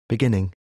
13. beginning (n.) /bi’giniɳ/ phần đầu, lúc bắt đầu, lúc khởi đầu